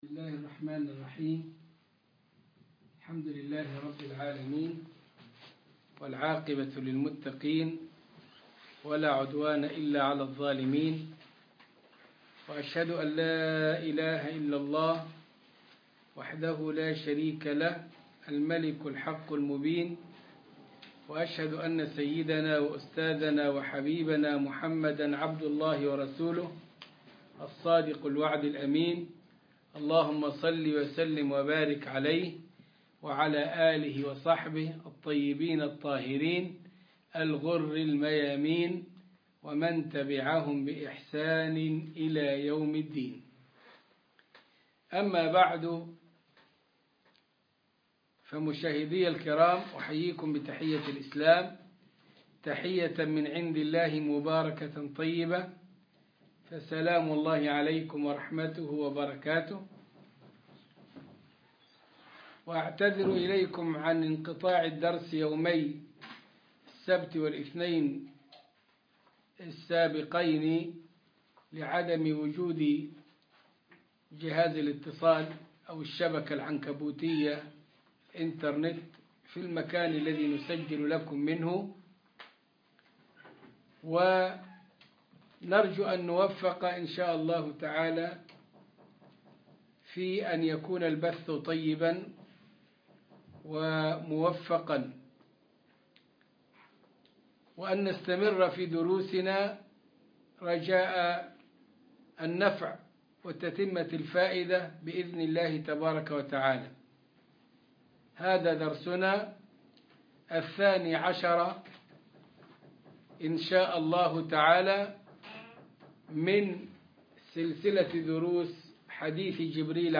عنوان المادة الدرس ( 12 ) ( شرح حديث جبريل عن الاسلام والايمان والاحسان) تاريخ التحميل الأحد 28 فبراير 2021 مـ حجم المادة 22.75 ميجا بايت عدد الزيارات 259 زيارة عدد مرات الحفظ 120 مرة إستماع المادة حفظ المادة اضف تعليقك أرسل لصديق